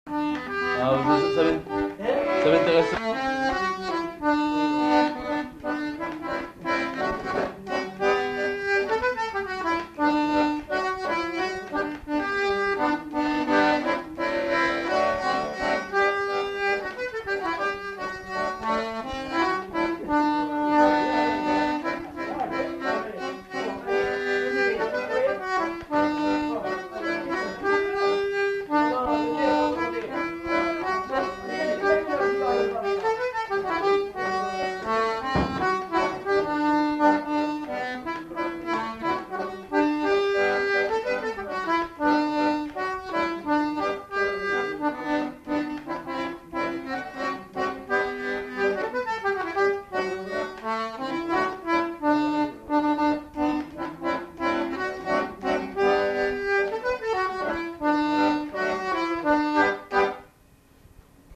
Répertoire d'airs à danser du Marmandais à l'accordéon diatonique
enquêtes sonores
Valse